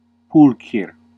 Ääntäminen
Classical: IPA: /ˈpul.kʰer/